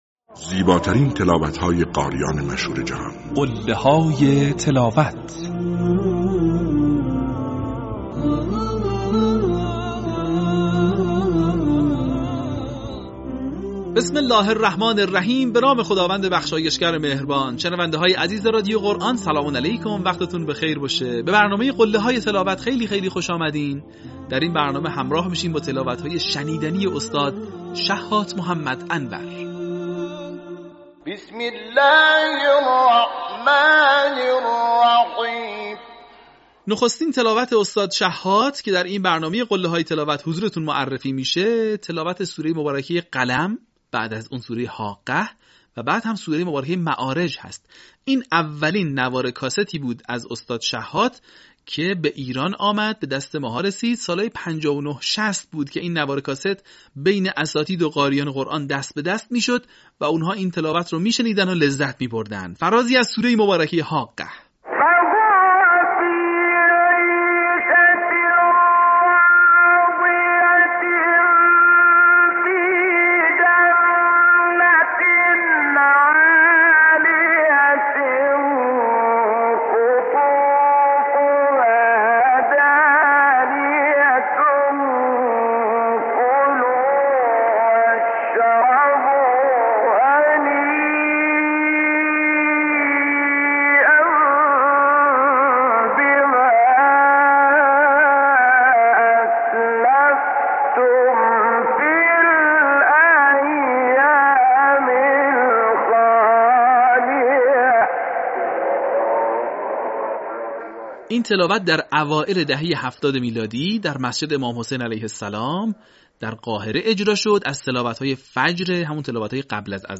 این مجموعه شامل تلاوت‌های ماندگار قاریان بین‌المللی مصری است که تاکنون 40 قسمت آن از ایکنا منتشر شده است.
قله‌های تلاوت شحات محمد انور